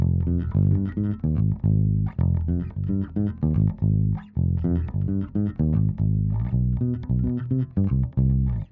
13 Bass PT 1-4.wav